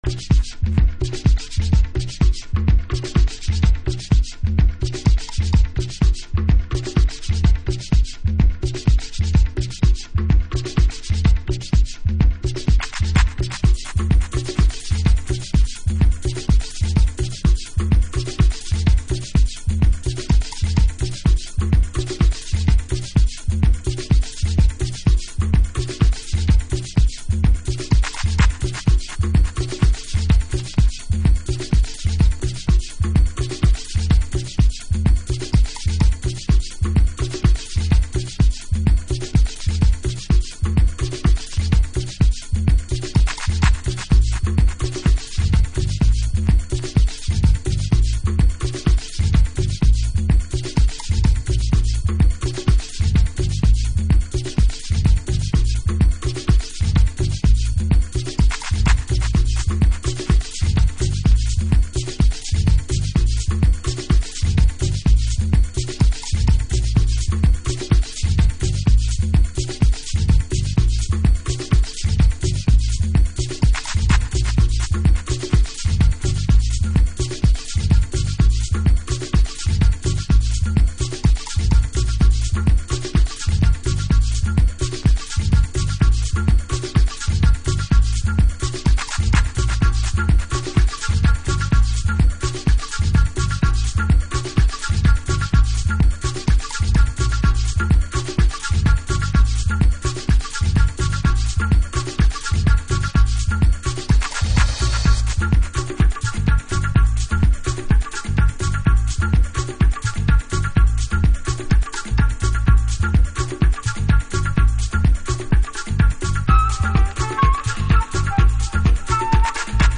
Early House / 90's Techno
10分越えのセッション。NY DEEPクラシック、プロモ盤。